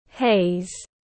Haze /heɪz/